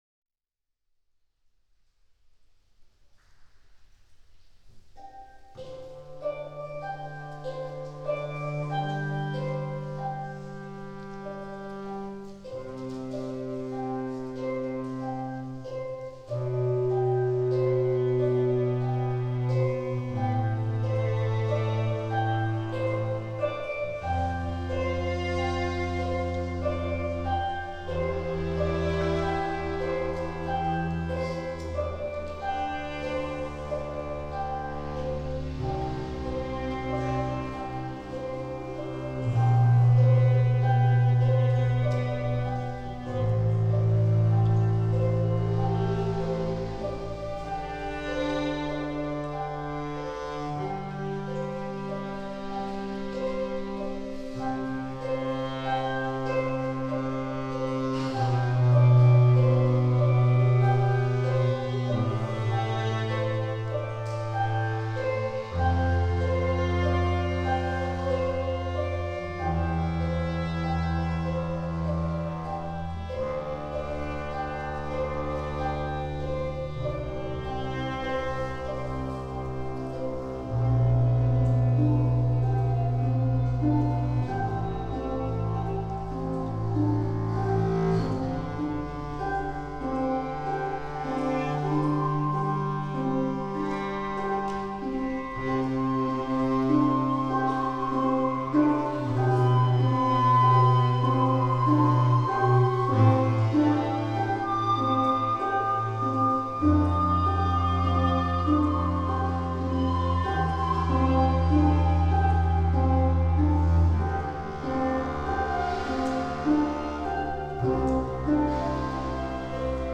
Headphones highly recommended for these binaural recordings
Chaccone concluded our 2017 Underground Music Festival.
double bass
percussion
Yolngu Manikay
violin/viola
shakuhachi